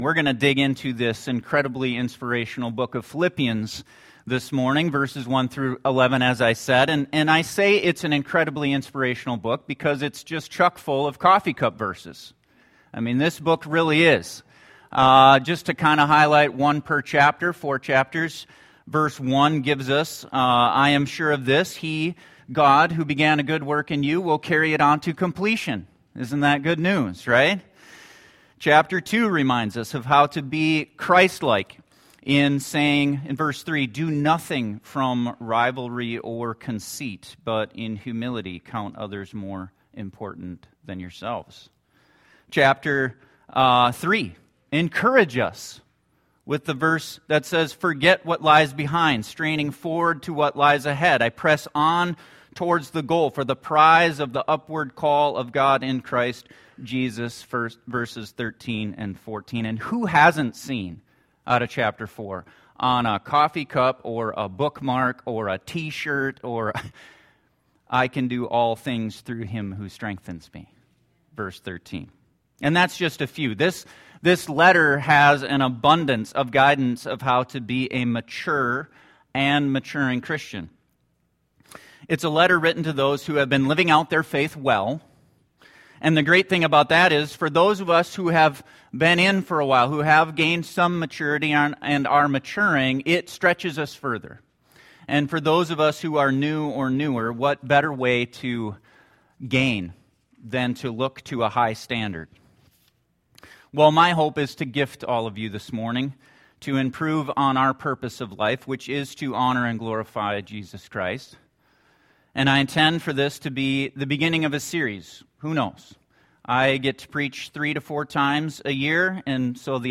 In this introductory sermon to the book of Philippians you will get a behind-the-scenes look at why Paul’s life took some unexpected twists and turns.